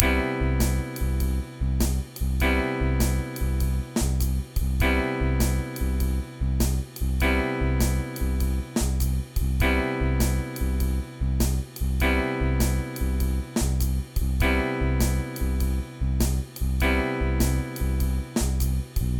in the blues style at 100 bpm